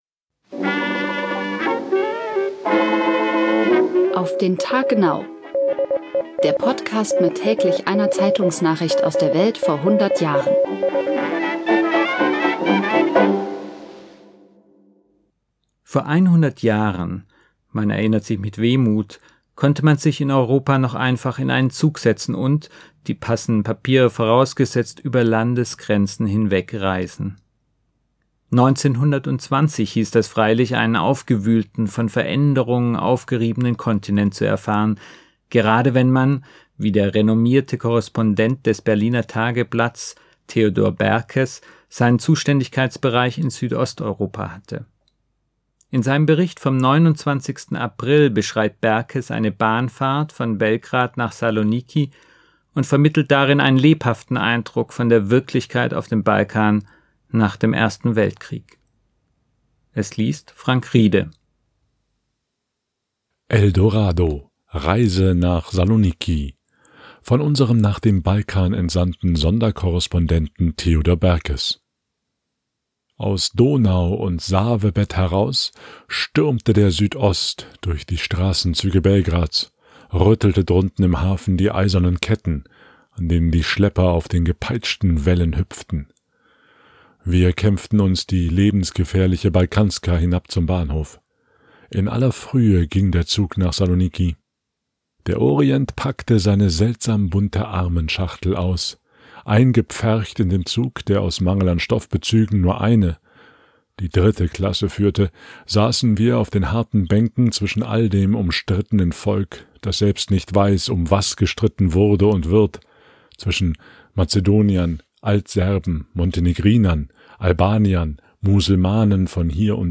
In seinem Bericht vom 29. April beschreibt Berkes eine Bahnfahrt von Belgrad nach Saloniki und vermittelt darin einen lebhaften Eindruck von der Wirklichkeit auf dem Balkan nach dem Ersten Weltkrieg. Es liest